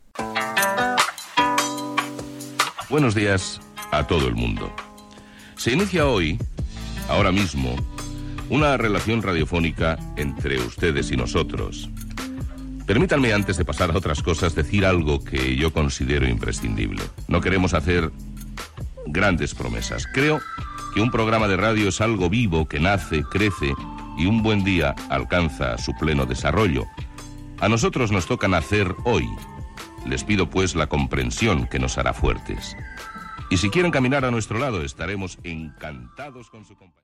Presentació del primer programa
Entreteniment